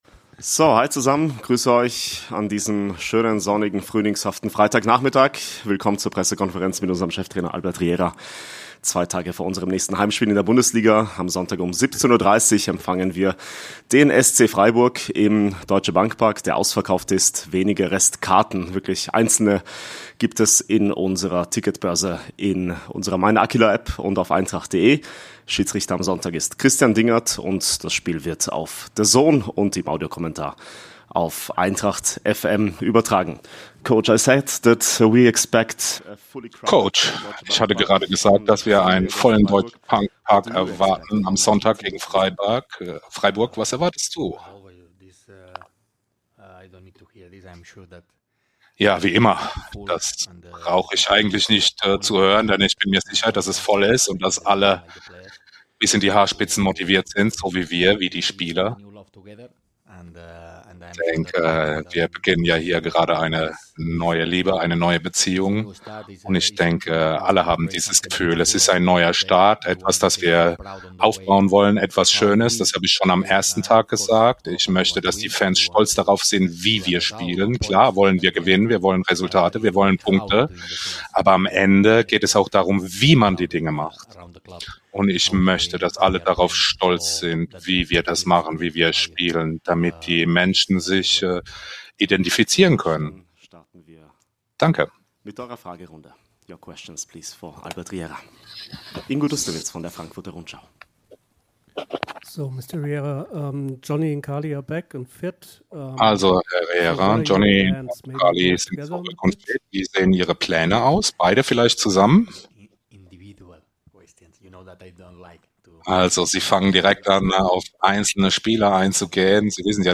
Trainer Albert Riera vor dem Heimspiel gegen den SC Freiburg